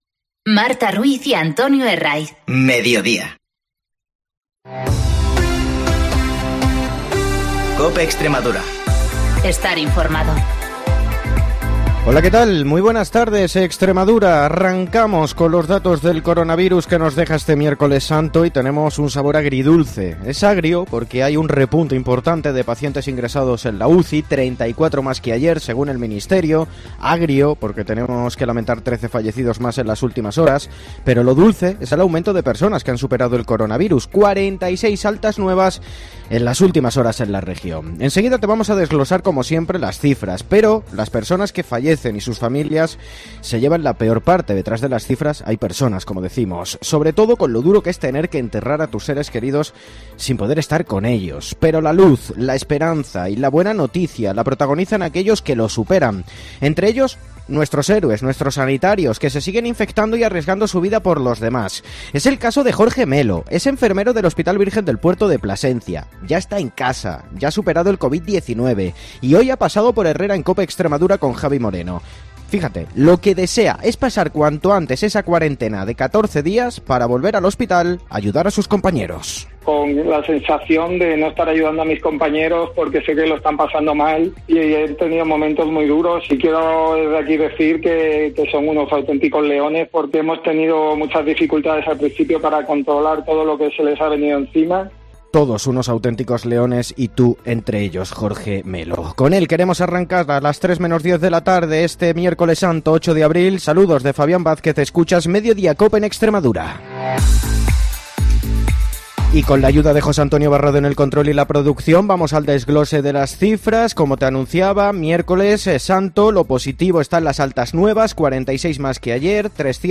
mediante teletrabajo cumpliendo así el Estado de Alarma